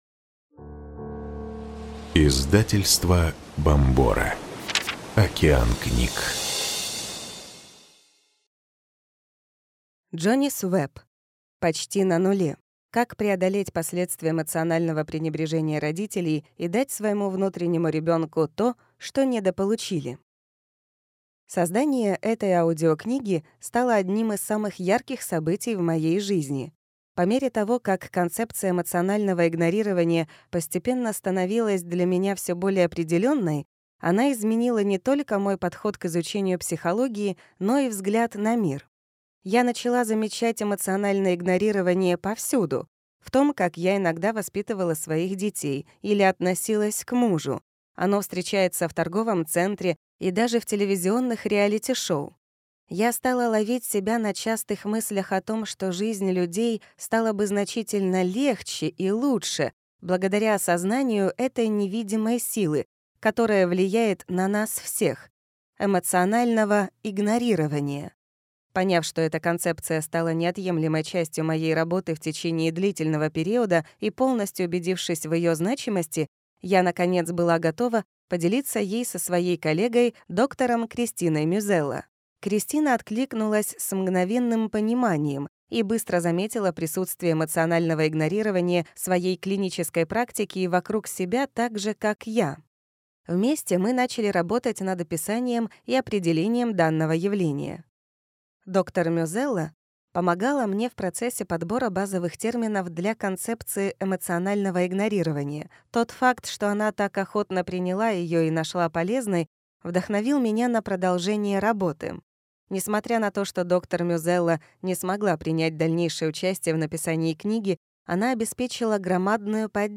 Аудиокнига Почти на нуле. Как преодолеть последствия эмоционального пренебрежения родителей и дать своему внутреннему ребенку то, что недополучили | Библиотека аудиокниг